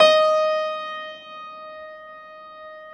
53c-pno15-D3.wav